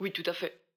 VO_ALL_Interjection_16.ogg